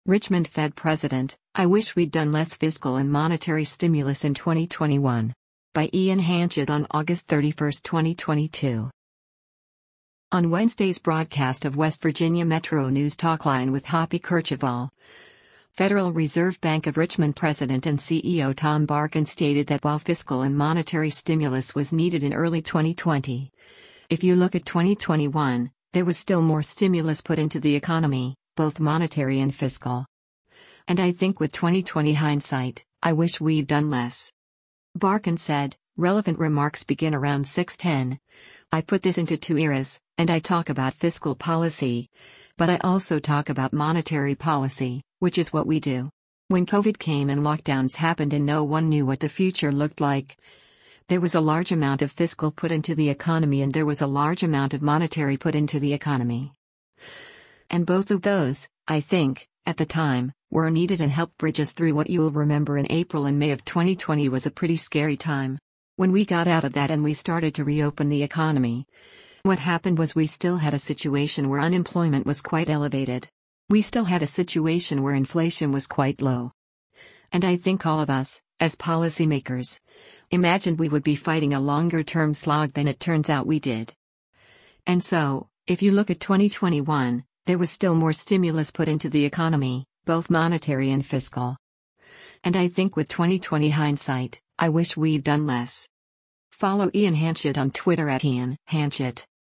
” Federal Reserve Bank of Richmond President and CEO Tom Barkin stated that while fiscal and monetary stimulus was needed in early 2020